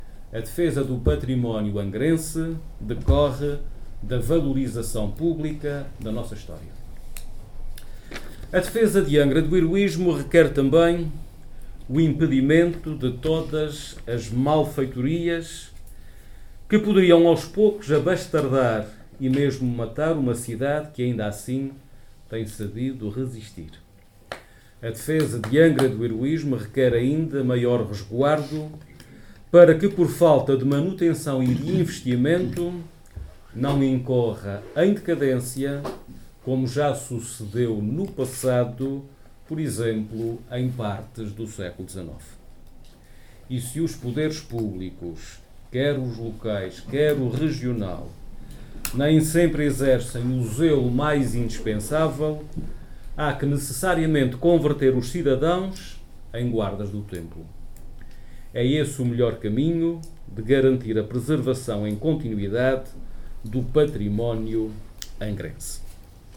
Avelino Meneses, que falava segunda-feira numa sessão pública promovida pelo Instituto Histórico da Ilha Terceira, salientou que a defesa de Angra do Heroísmo “requer também o impedimento de todas as malfeitorias que poderiam aos poucos abastardar e mesmo matar uma cidade que, ainda assim, tem sabido resistir”.